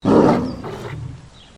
Lion Bark Single Shot
SFX
yt_xoqOtNRvrJo_lion_bark_single_shot.mp3